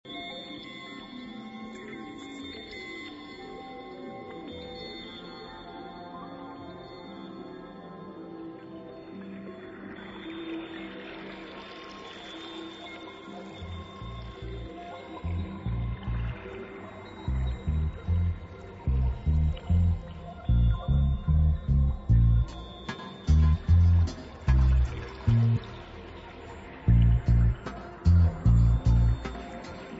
• musica remix
• reggae